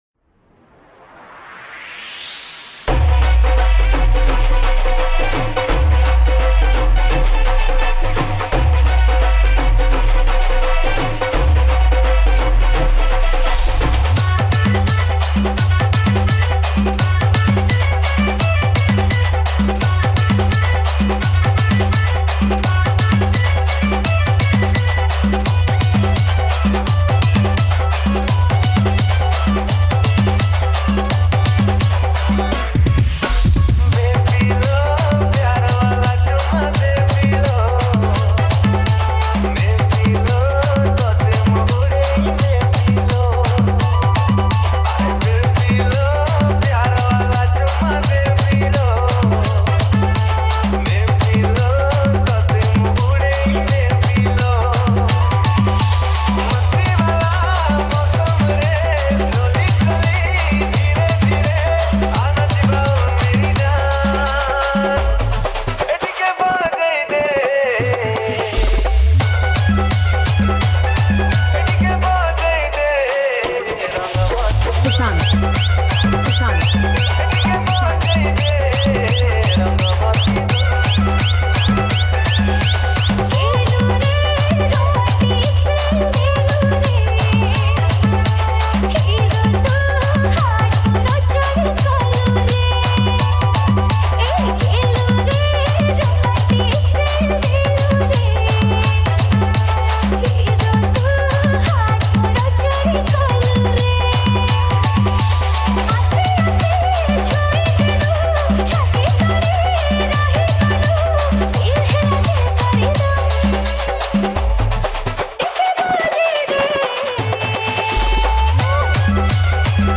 Category:  New Odia Dj Song 2022